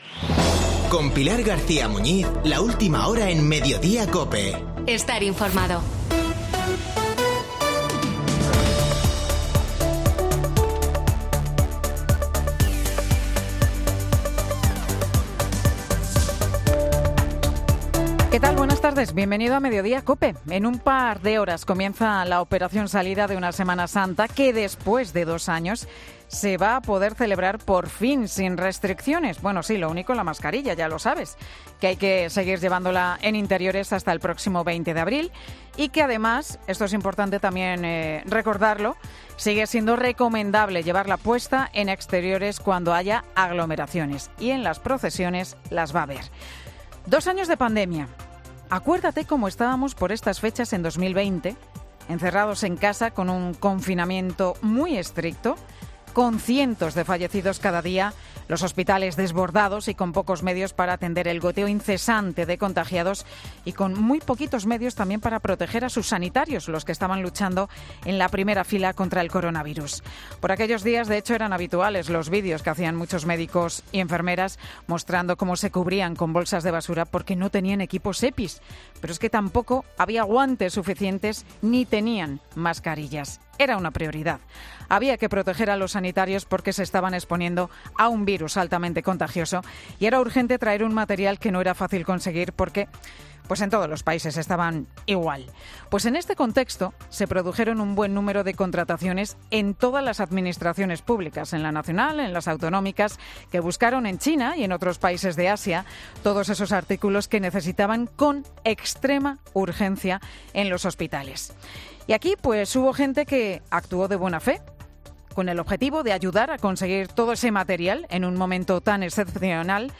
El monólogo de Pilar García Muñiz en Mediodía COPE